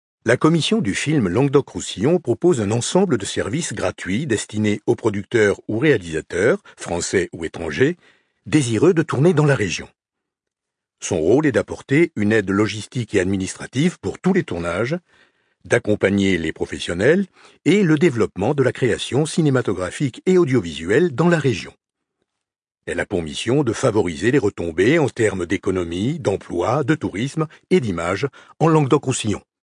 Voix d'homme en français ⋆ Domino Studio